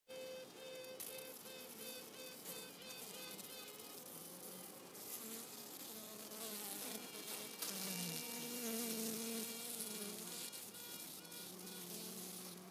Tüten und Quäken der Bienenköniginnen
Schön war es, hierbei das Tüten uns Quäken der Königinnen zu hören: